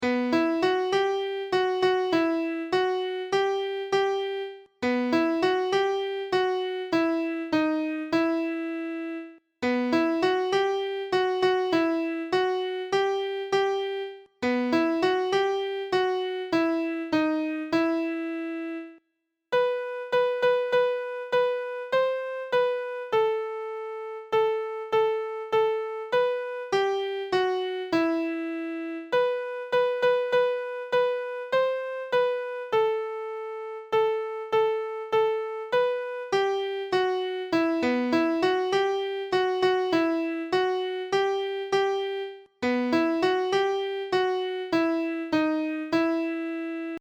2 part round.